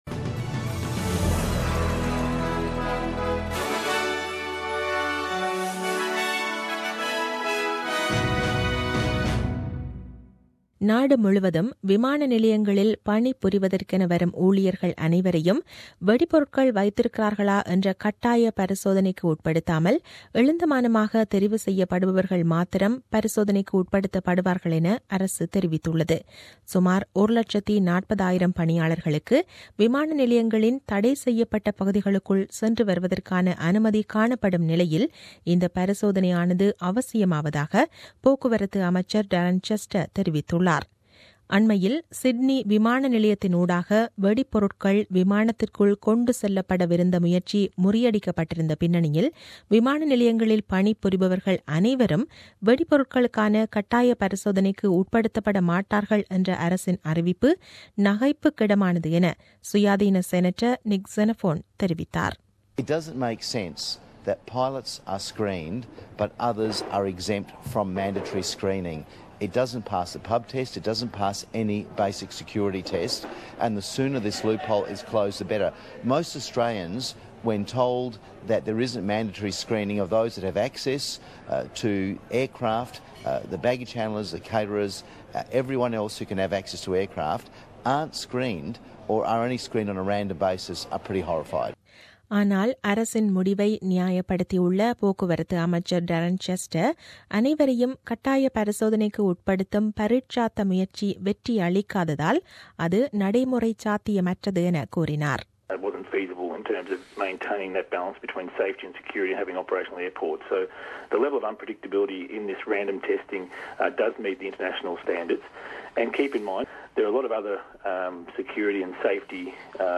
The news bulletin aired on 22 October 2017 at 8pm.